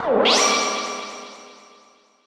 snd_great_shine.ogg